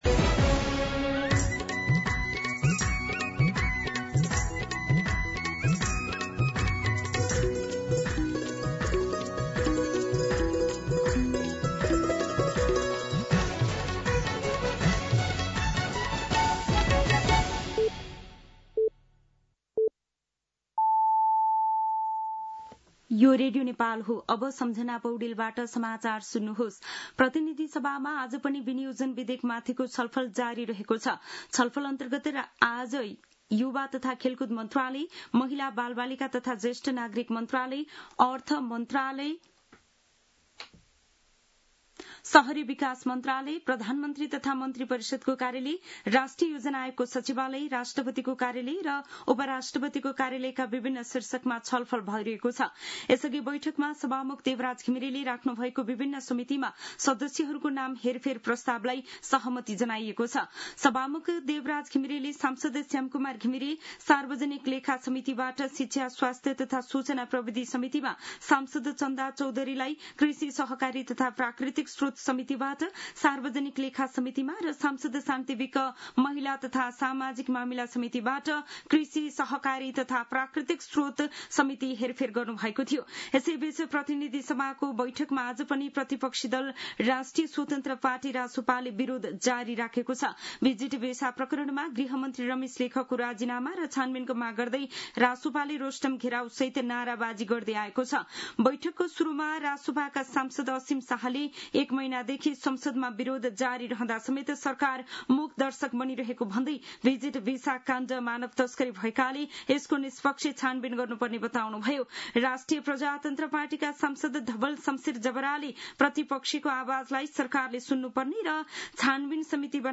दिउँसो १ बजेको नेपाली समाचार : ८ असार , २०८२
1pm-News-08.mp3